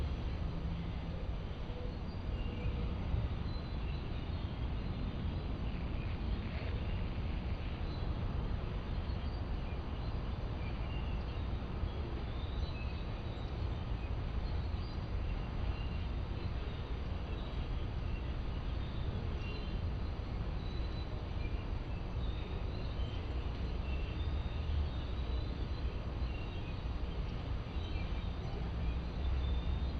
Early Evening Suburbs Neighborhood Evening Birds Distant Air Conditioner Hum ST450_ambiX.wav